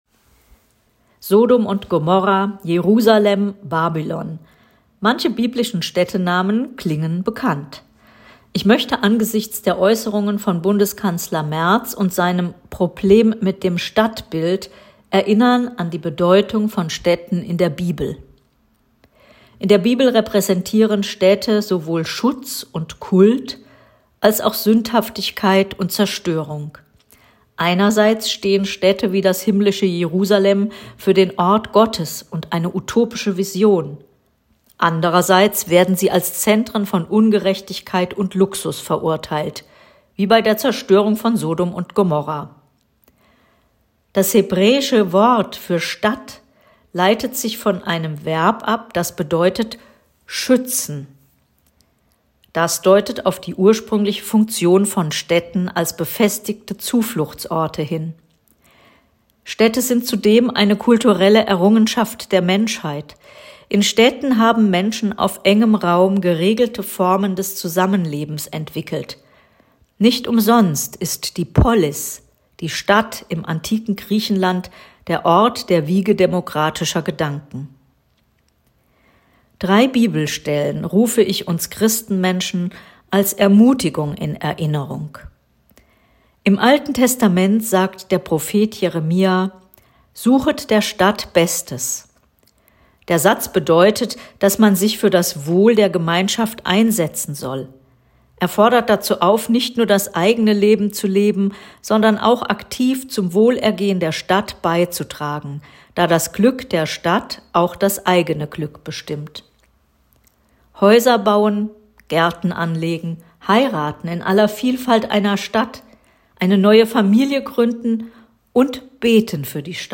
2025 Andacht zum "Stadtbild"
Andacht_Stadtbild.mp3